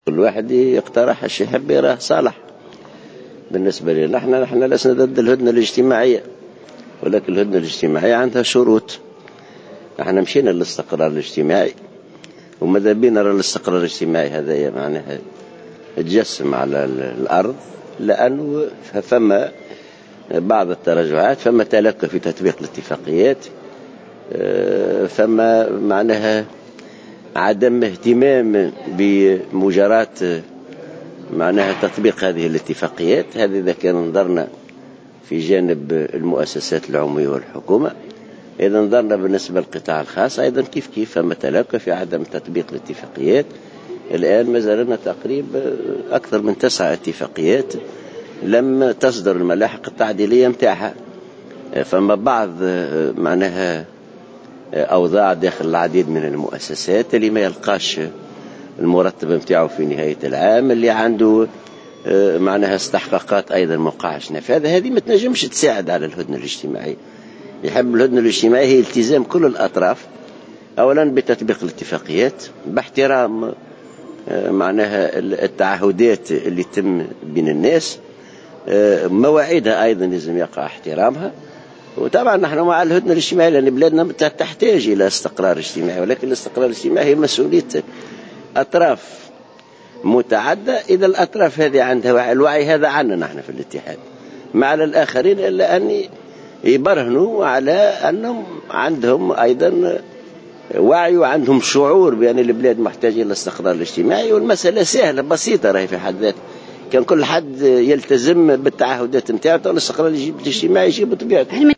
وقال العباسي في تصريح اليوم لمراسلة "الجوهرة أف أم" على هامش مؤتمر الجامعة العامة للمياه المنعقد في الحمامات، إن الإتحاد ليس ضد الهدنة لكن شريطة الالتزام بتطبيق الاتفاقيات واحترام التعهدات.